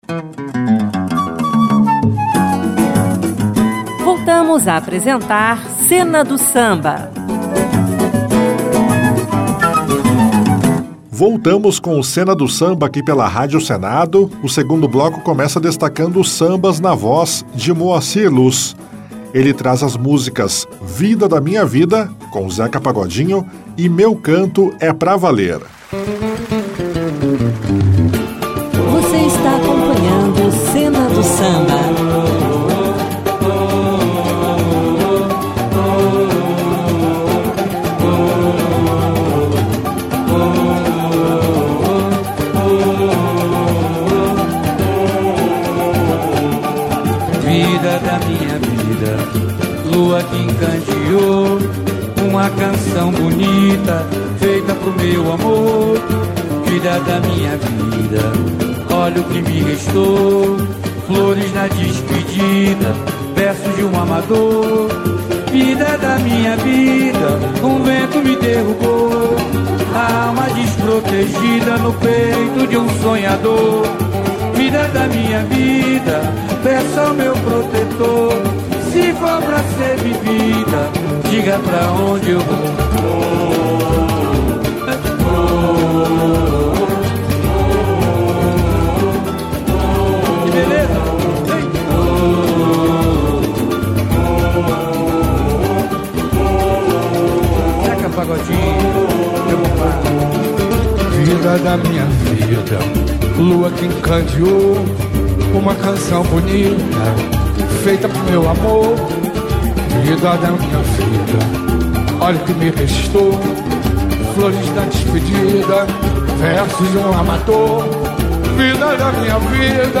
Você vai ouvir no programa alguns sambas clássicos